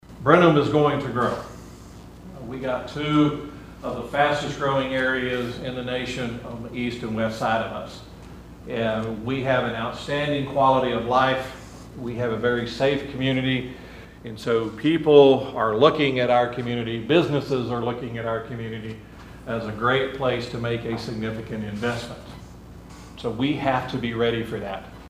City of Brenham officials provided a general overview of the proposed tax rate and budget for the new fiscal year at a town hall meeting Wednesday.
City Manager James Fisher said the city shaped this year’s budget by answering one question in particular: how does it continue to prepare for growth?